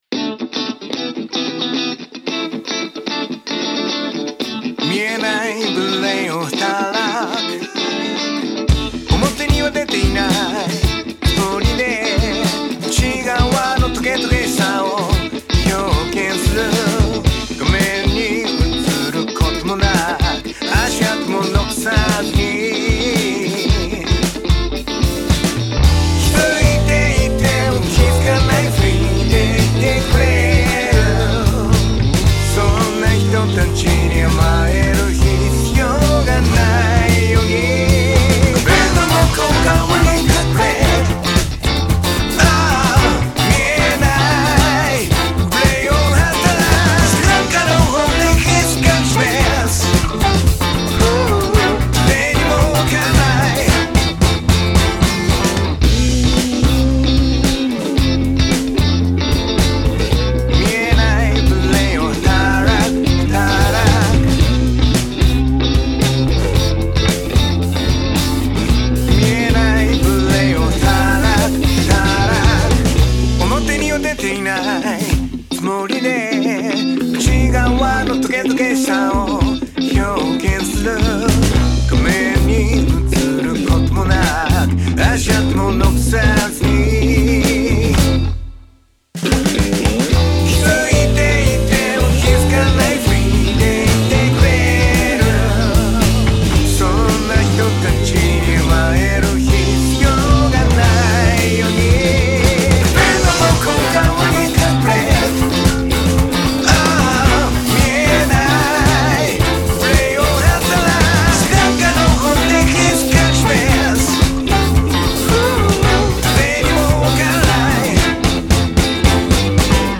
ミュージック
毎日1曲、新曲つくってアレンジ＆録音したものを日々配信中。